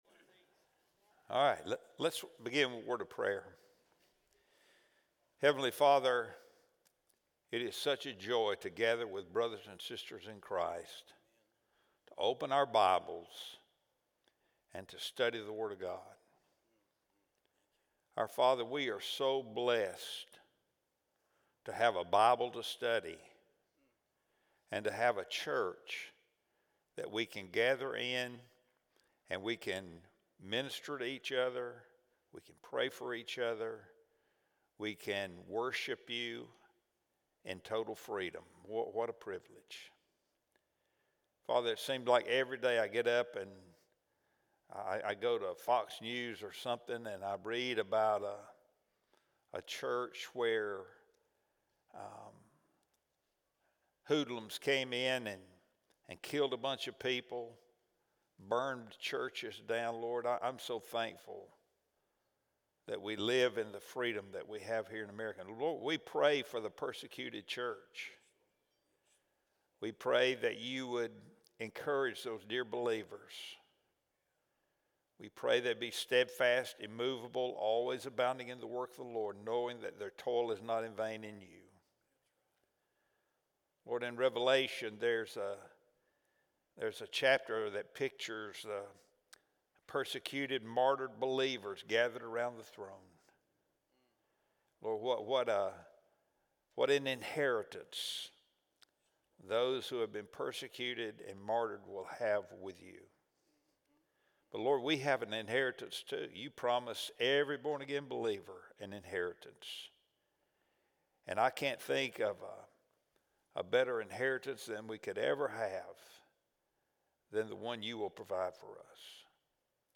Wednesday Bible Study | July 23, 2025 | Sermon on the Mount | Session #17 – Collierville First Baptist Church